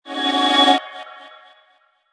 MicroSound_alert.ogg